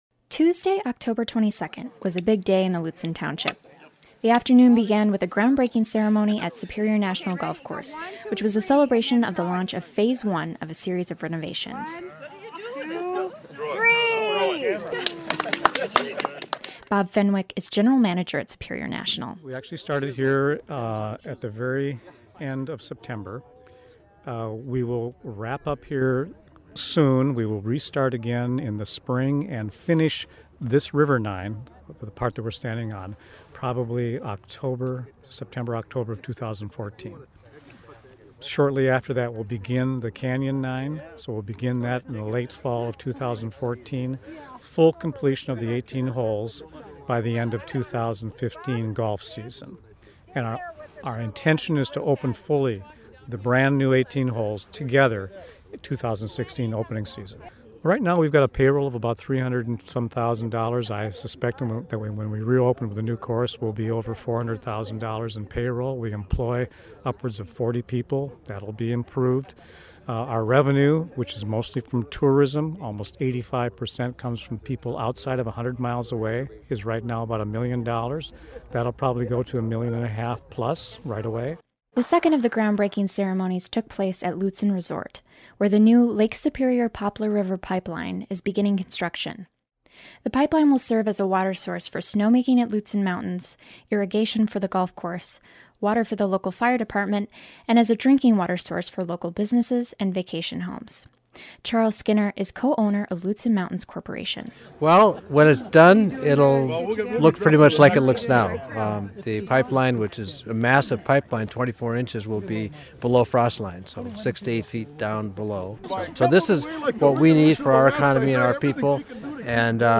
has this report from the events.